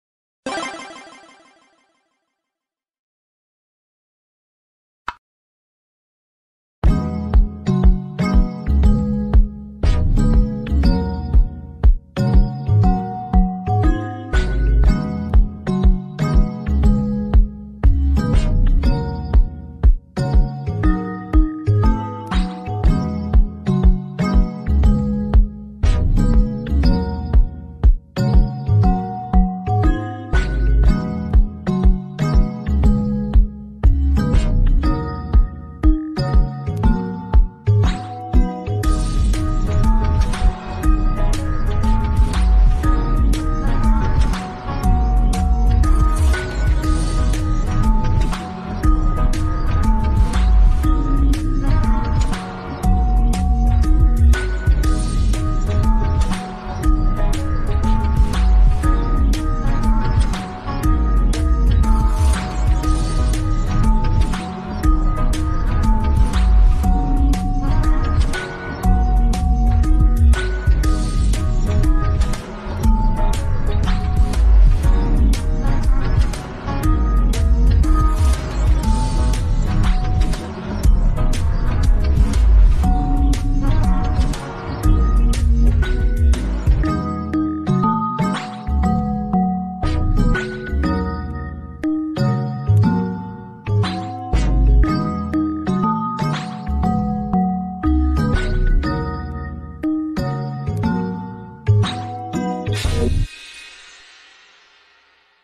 (Low Pitch)